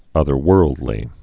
(ŭthər-wûrldlē)